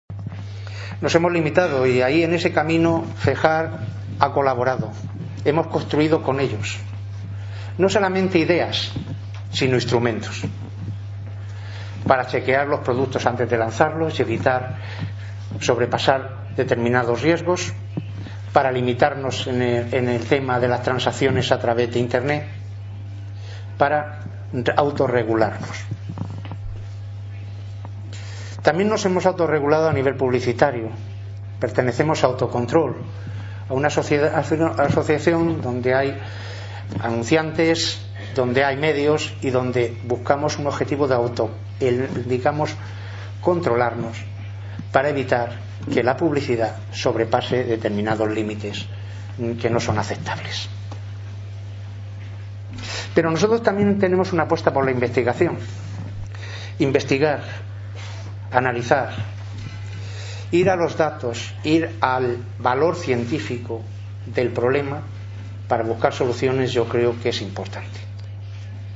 FEJAR promueve una nueva jornada científica sobre la problemática social del Juego